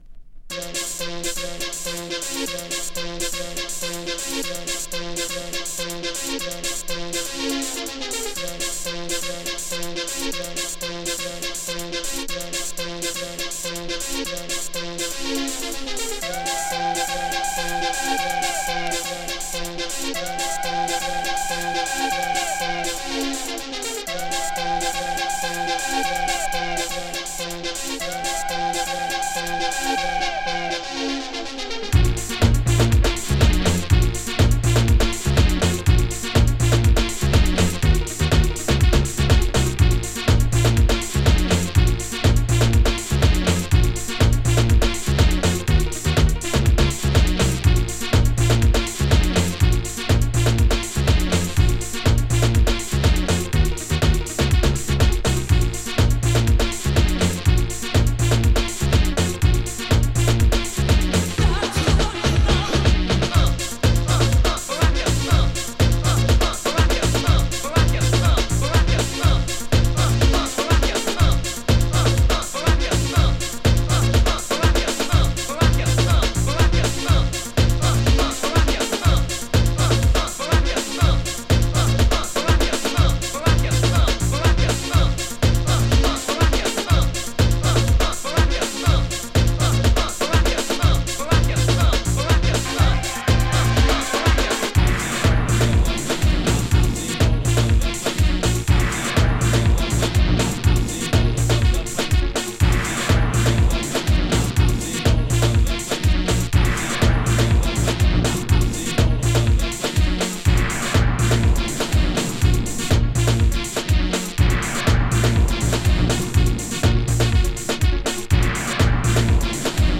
[HOUSE] [FREE STYLE]
HIP HOUSE!